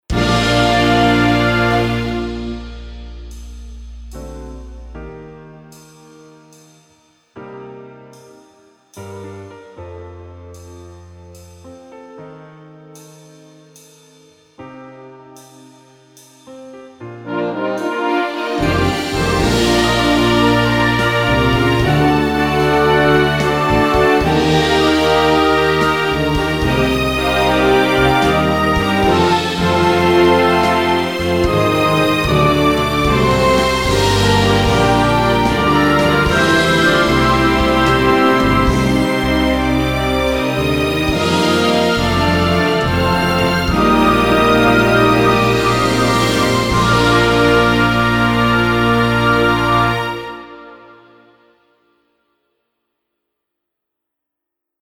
Refrão 3
Finalizando o arranjo com uma grandiosidade majestosa.